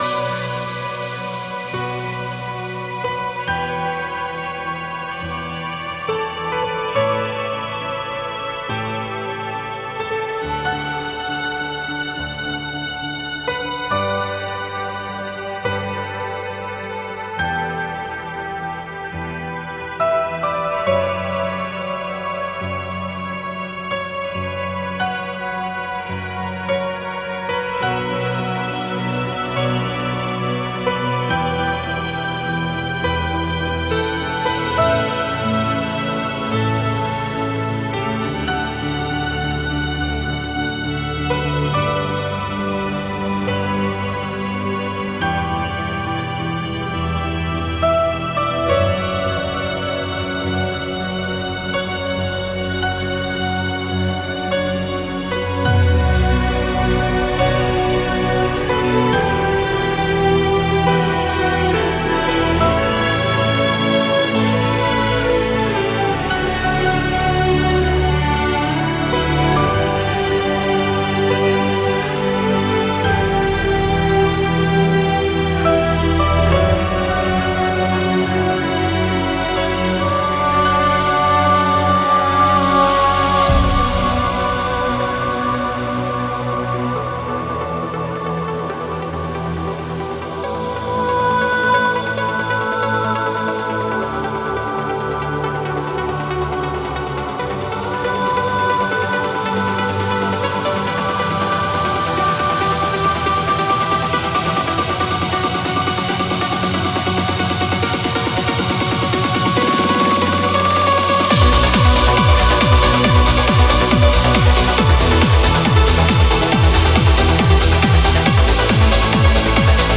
Категория: Электро музыка » Транс